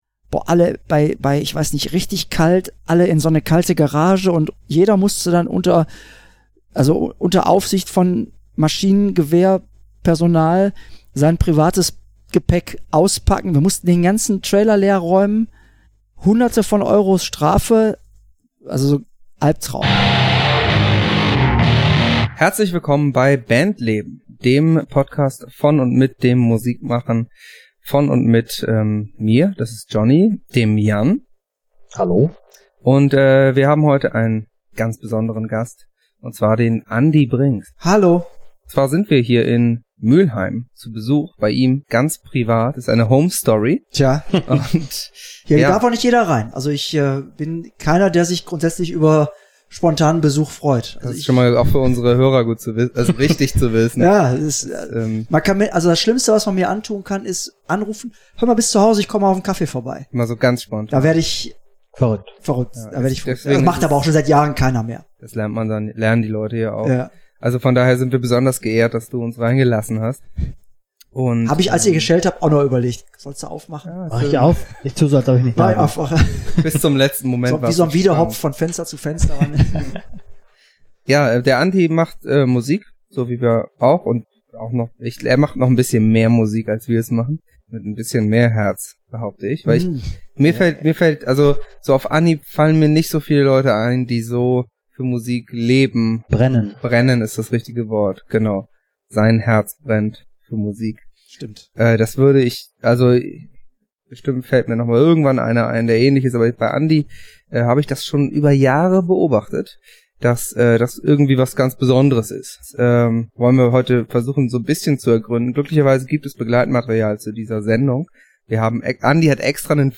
*Die etwas schlechtere Audioqualität im Verhältnis zu sonst bitten wir zu entschuldigen, unser mobiles Setup muss noch etwas optimiert werden, wir arbeiten dran.